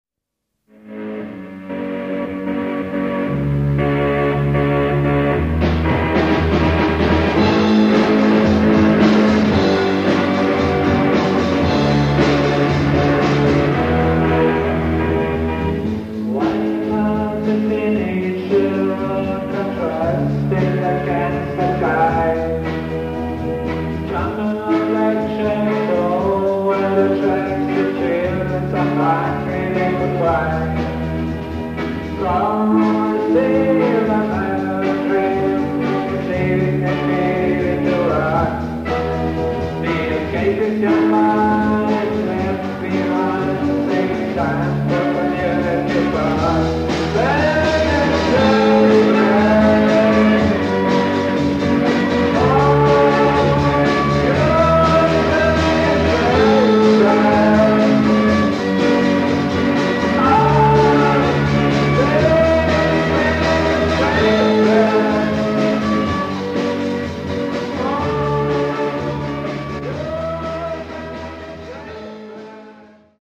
sångare
gitarr
trummor). Snart fick bandet också en organist
Så här lät det vid vår sista spelning 1969: